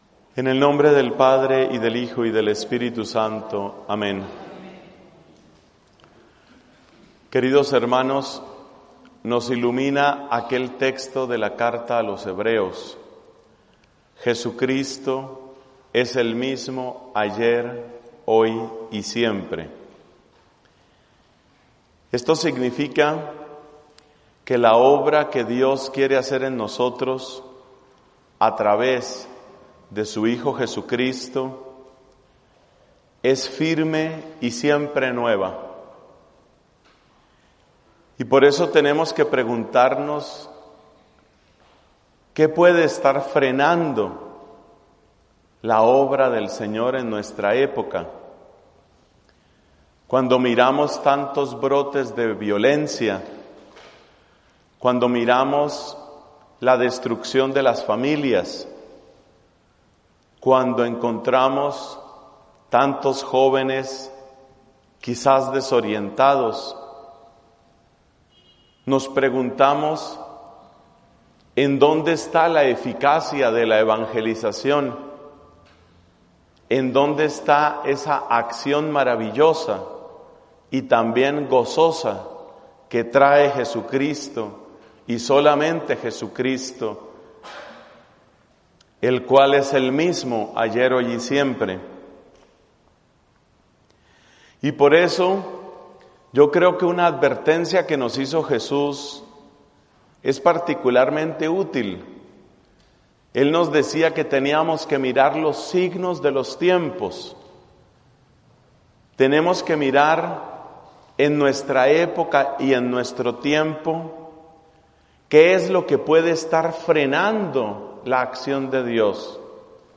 [Predicación en la Universidad de San Pablo, en San Luis Potosí, México]